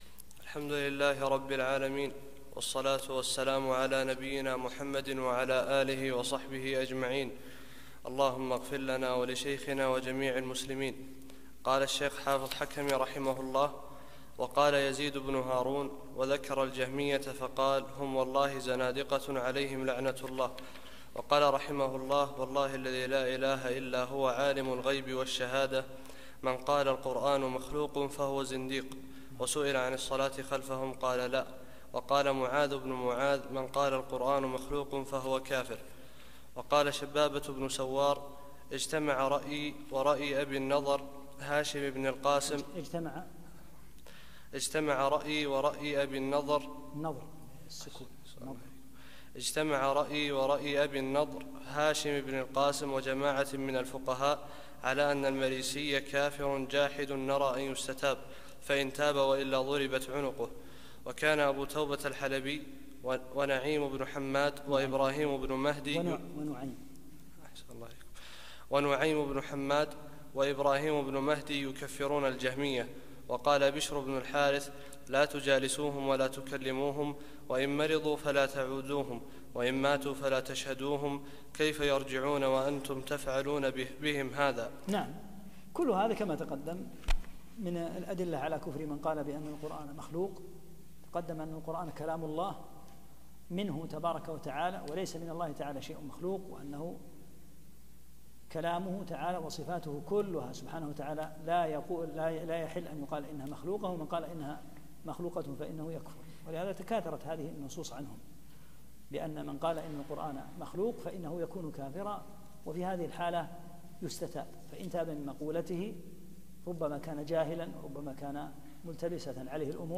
39- الدرس التاسع والثلاثون